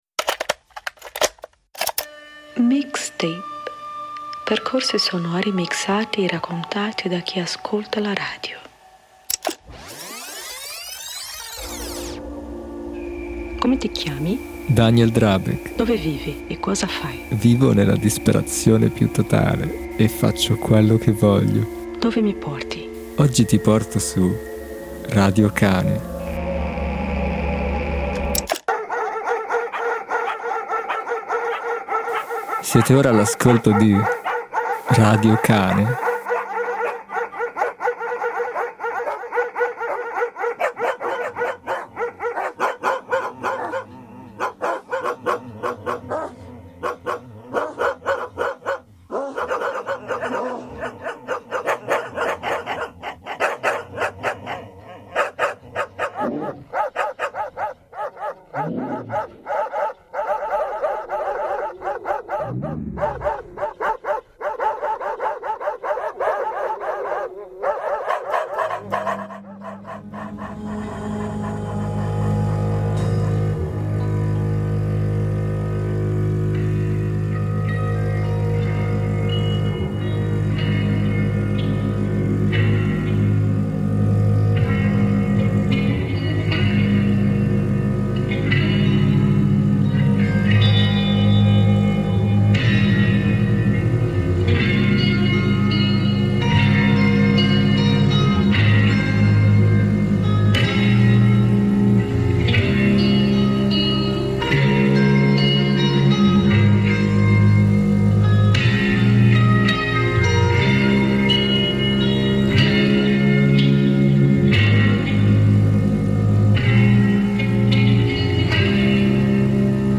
Trenta secondi di latrati di cani